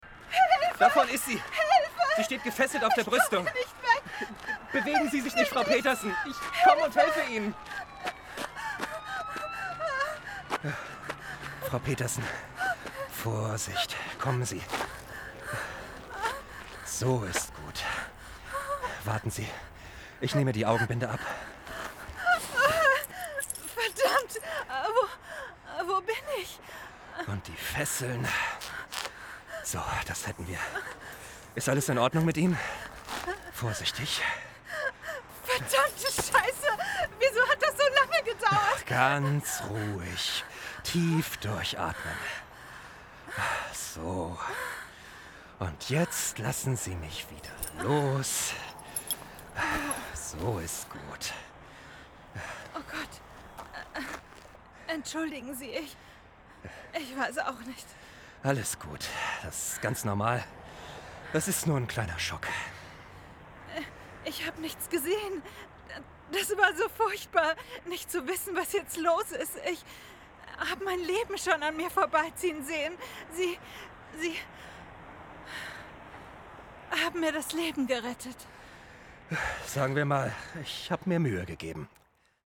Hörspiel (Krimi) – panisch
mitteltief ⋅ frisch ⋅ facettenreich
Horspieldemo_panisch.mp3